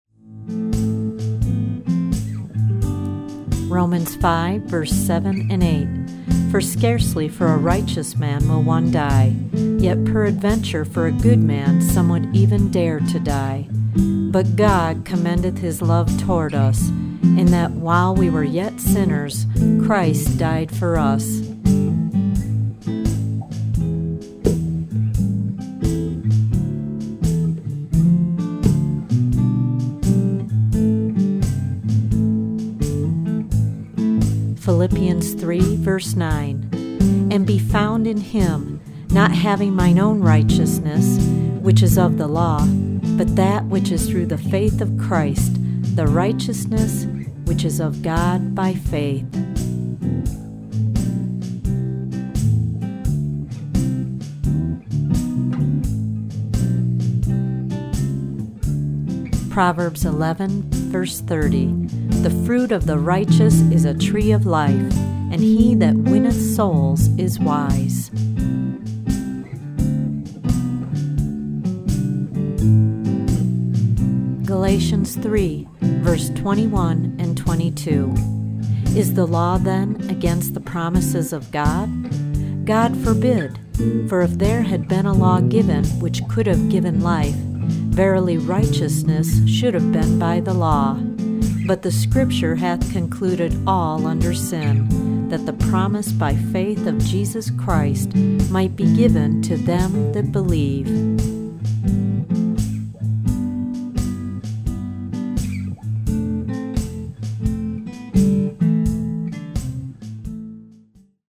original anointed instrumental music on six CD’s.